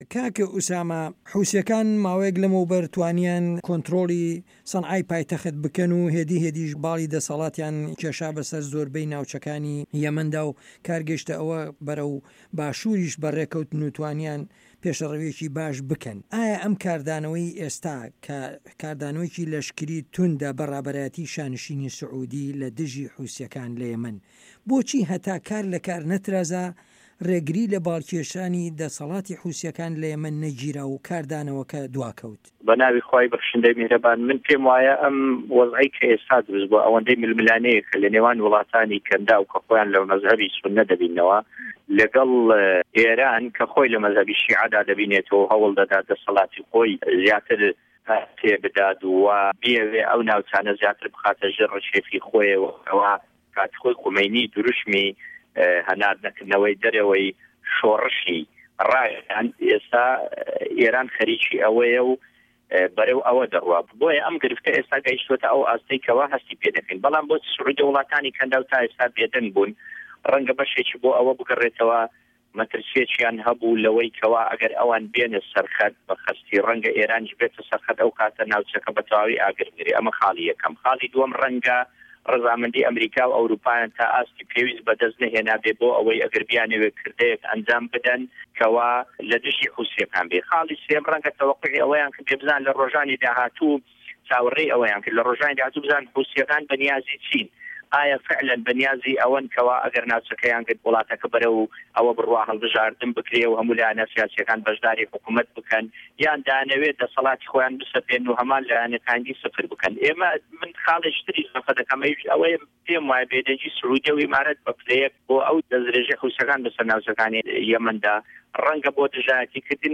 وتوێژ